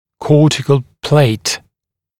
[‘kɔːtɪkl pleɪt][‘ко:тикл плэйт]кортикальная пластинка